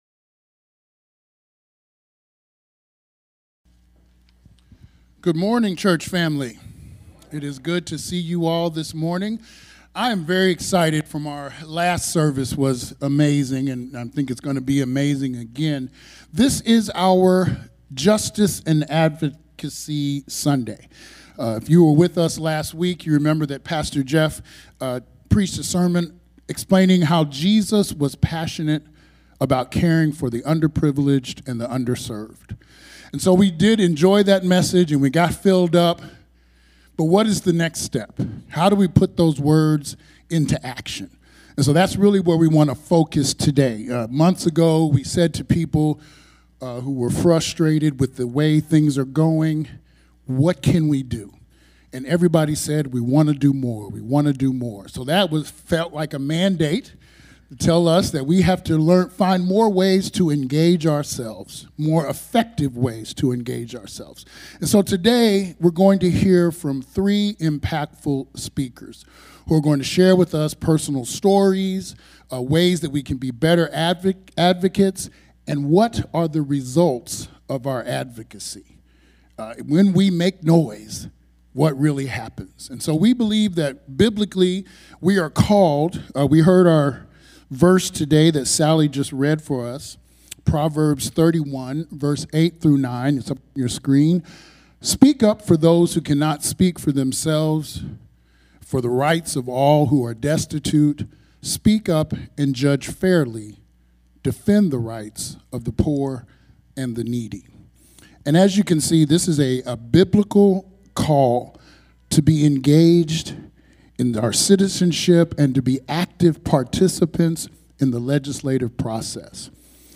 So during this Sunday's sermon time, three different expert guest speakers are going to share practical insights that will help us be more effective advocates for those who are marginalized -- so we can live the words of Jesus!